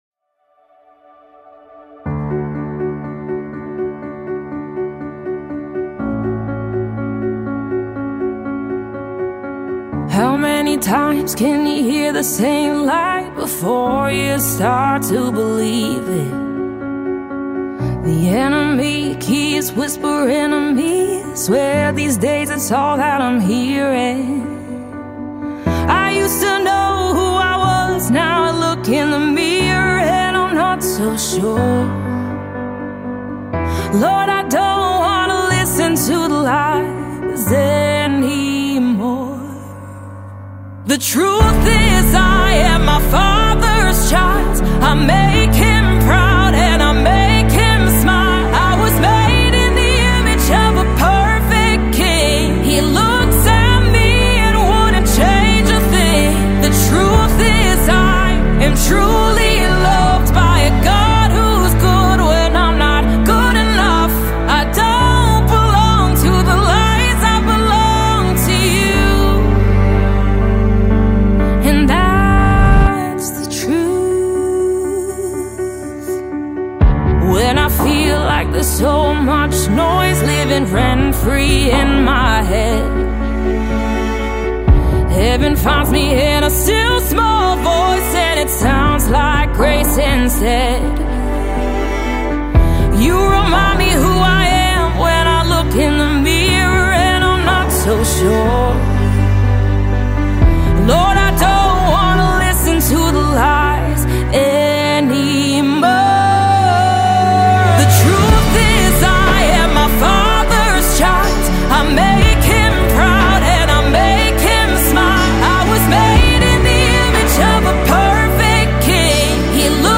Mp3 Gospel Songs
an emerging artist in the contemporary Christian music scene